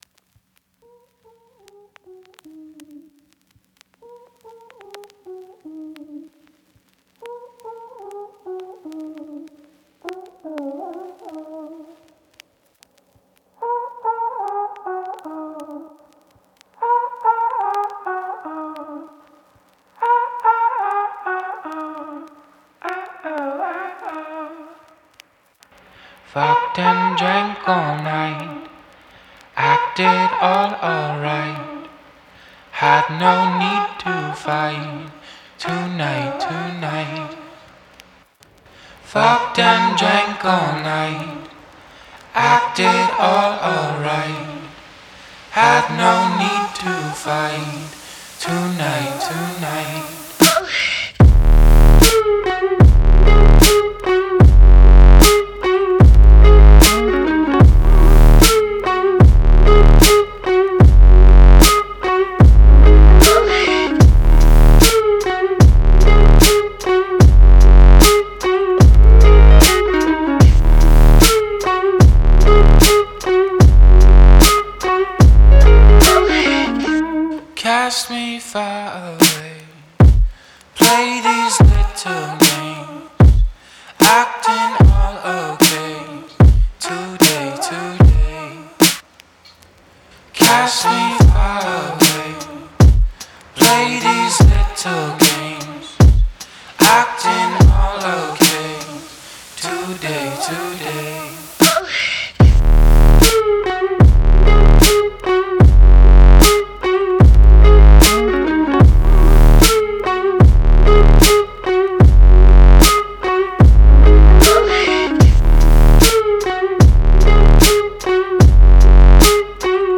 Dance Alternative indie